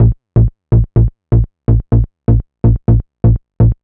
cch_bass_motor_125_Bb.wav